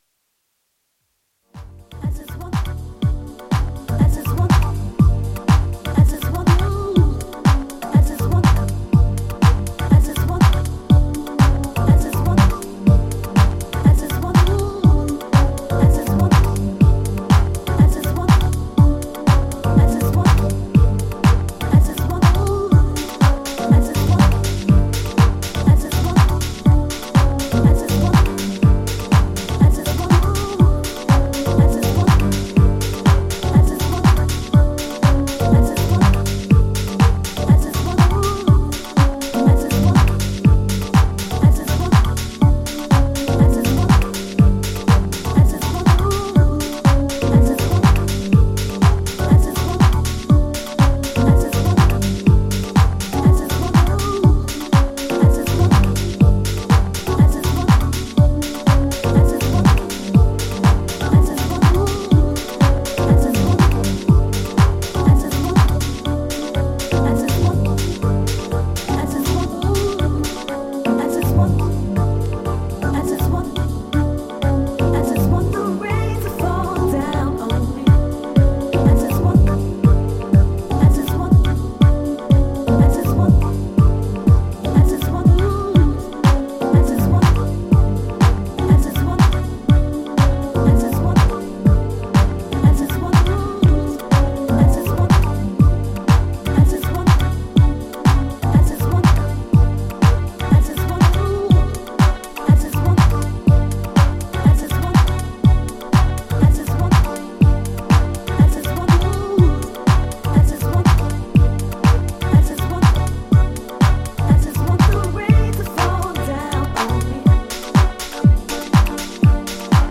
ジャンル(スタイル) SOULFUL HOUSE / JAZZY HOUSE